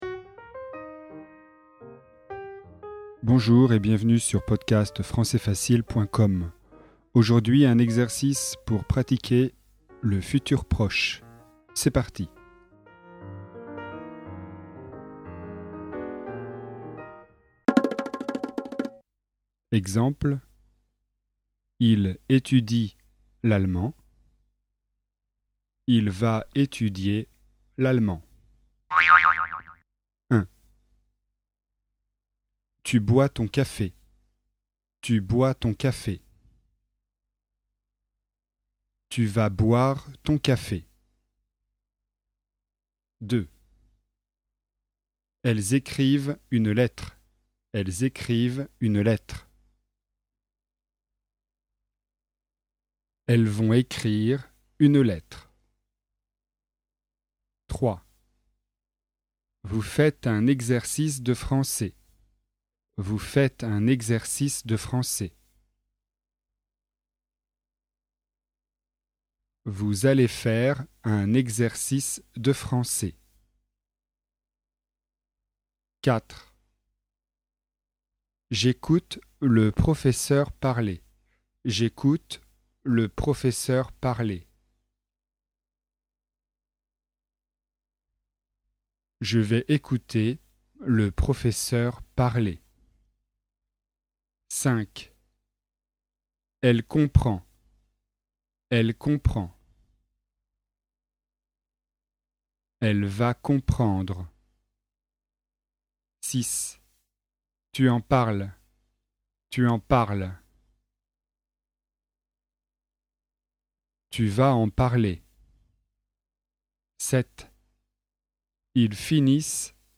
Exercice de grammaire, niveau débutant (A1) sur le thème du futur proche.